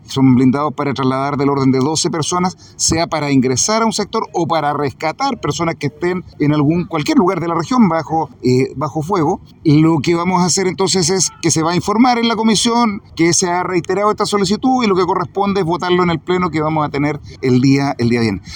El gobernador Regional, Rodrigo Díaz, agregó que se espera se pueda avanzar en este ítem y que este viernes se espera sea votado por el pleno del CORE.